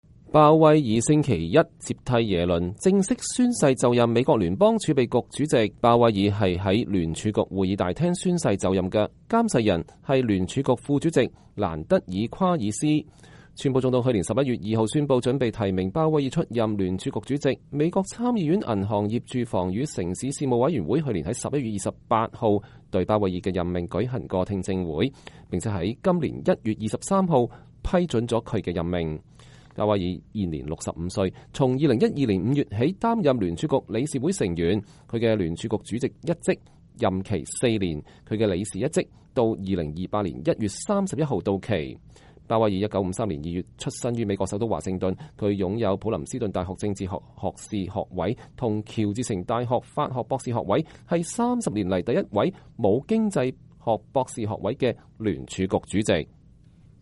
傑羅米鮑威爾星期一接替耶倫，正式宣誓就任美國聯邦儲備局主席。鮑威爾是在聯儲局會議大廳宣誓就任的，監誓人是聯儲局副主席蘭德爾夸爾斯。